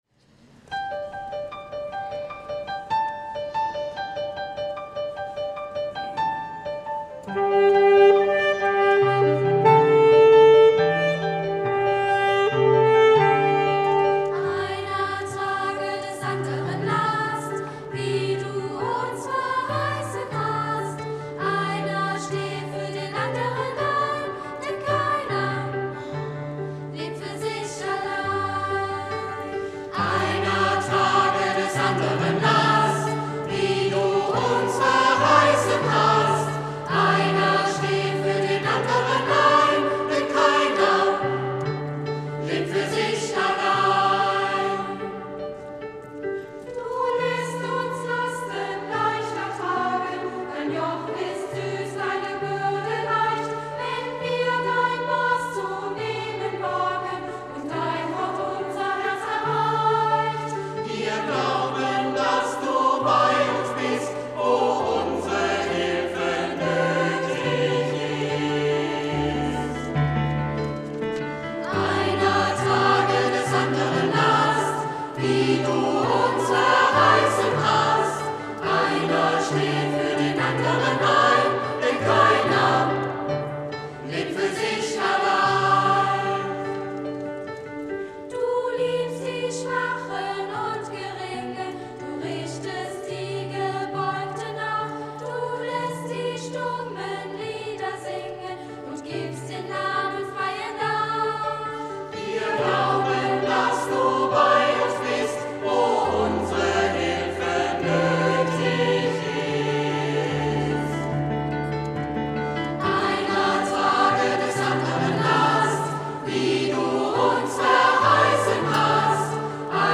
Musikalischer Abschluss der dritten Oktav 2013
Kinder- und Jugendchor
Projektchor
Saxophon